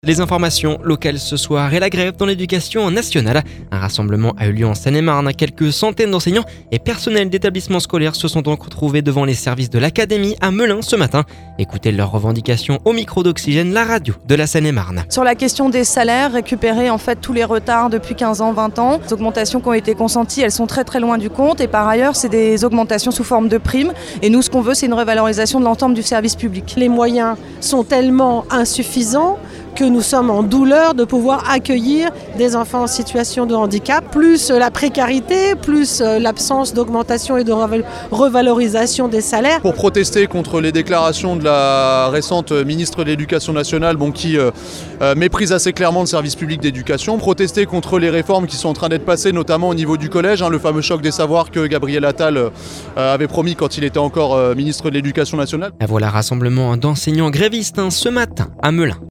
Leurs revendications au micro d'Oxygène, la radio de la Seine-et-Marne. Rassemblement d'enseignants grévistes ce matin à Melun.